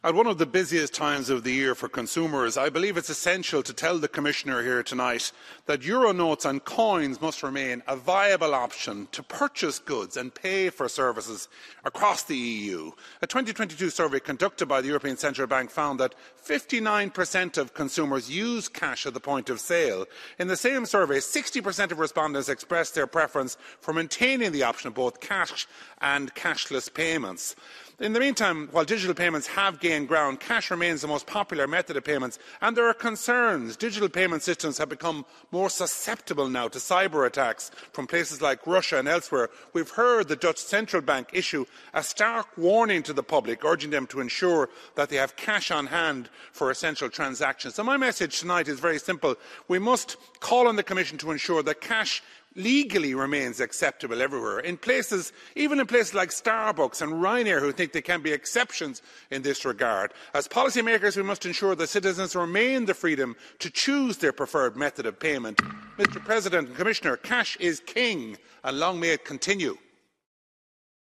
A Midlands Northwest MEP has told the European Parliament that efforts must be made to ensure that cash is still viable.